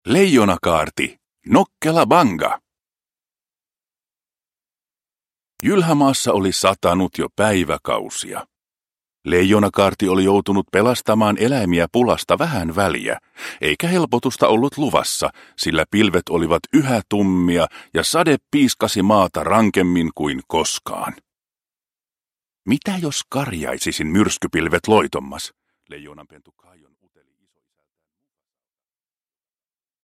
Leijonakaarti. Nokkela Bunga – Ljudbok – Laddas ner